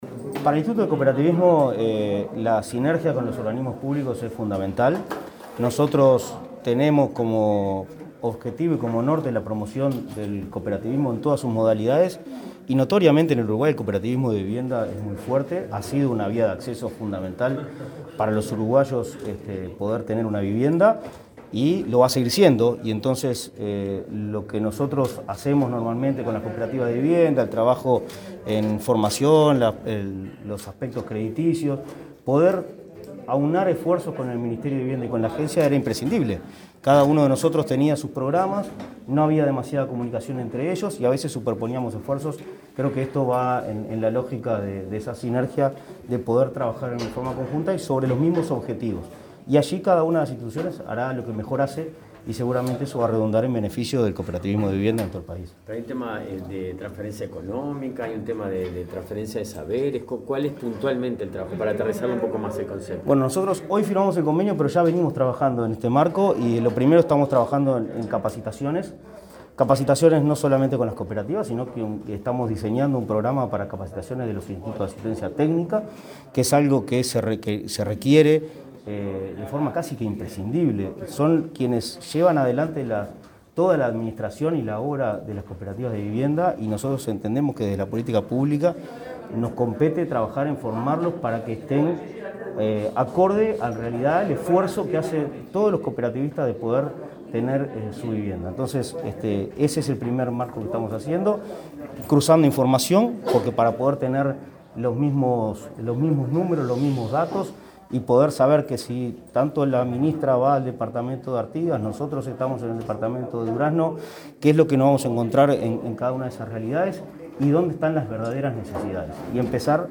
Declaraciones del presidente del Instituto Nacional del Cooperativismo, Martín Fernández, a la prensa